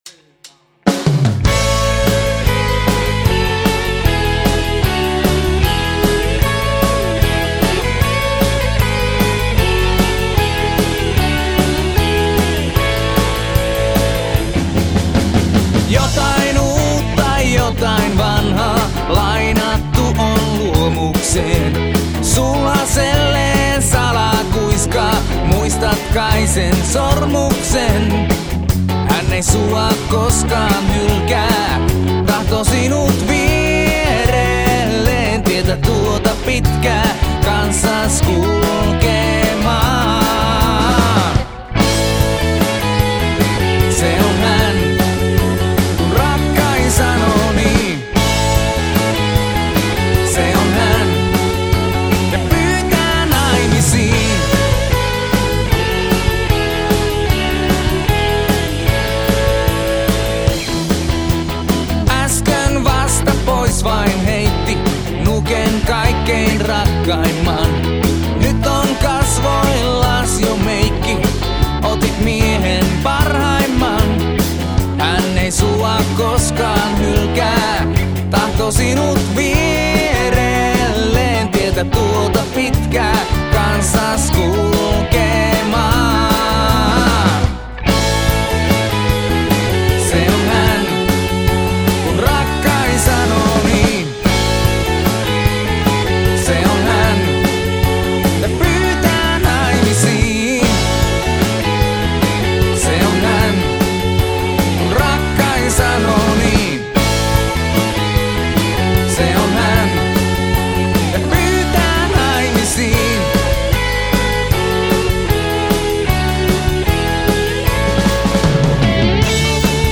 Live! + studiomodaus päälle!